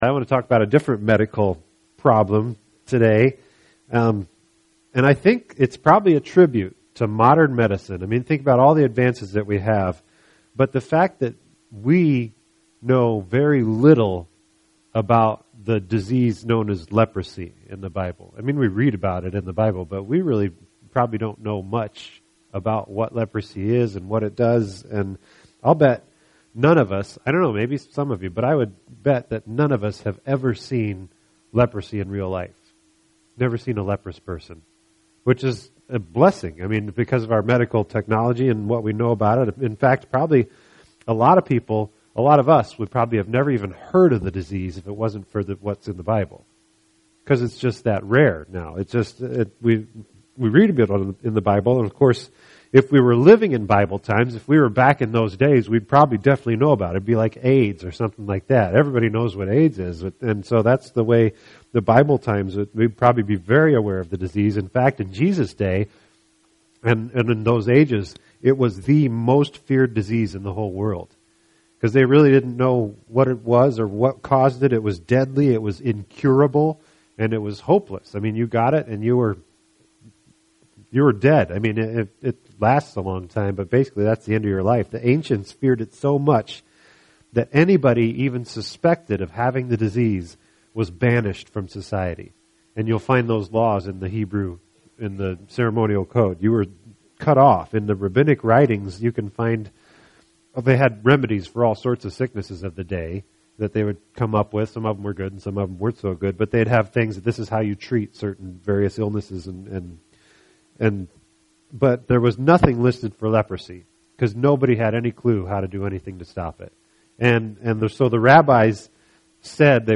This entry was posted on Friday, November 2nd, 2012 at 1:23 am and is filed under Sermons.